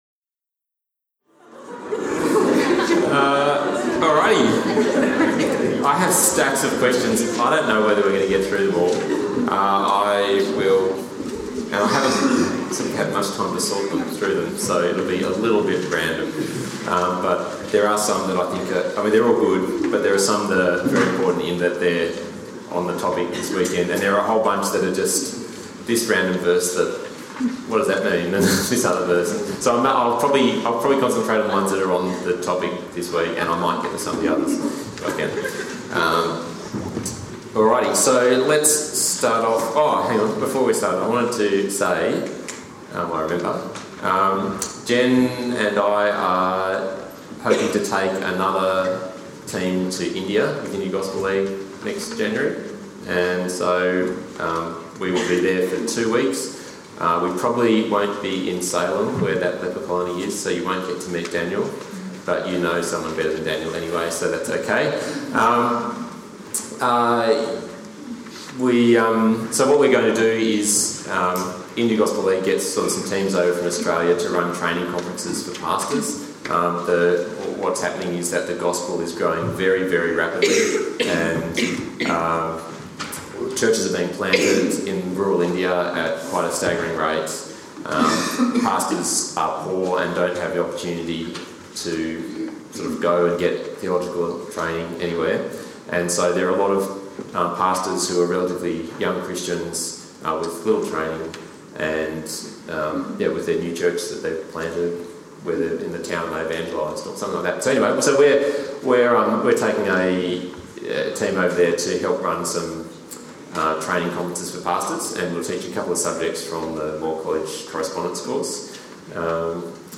Cross Examination: Question Time (WC2011)